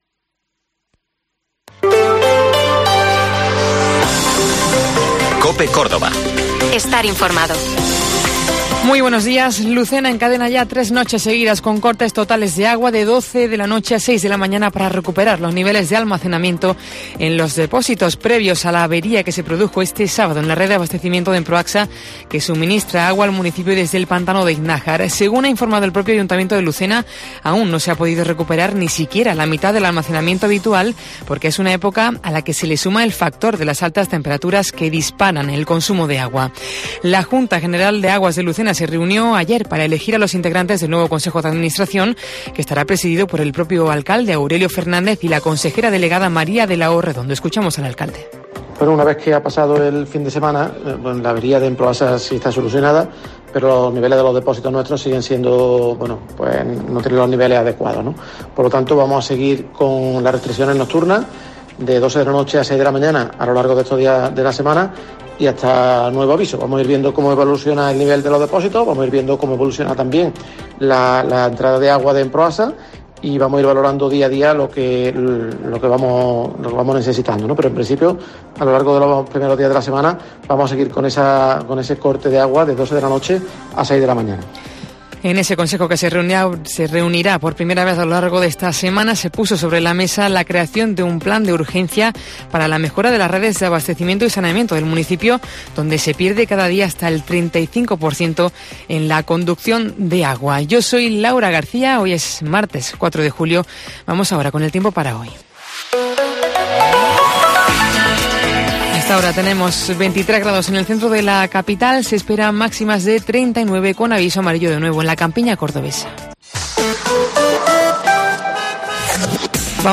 Informativo Herrera en COPE Córdoba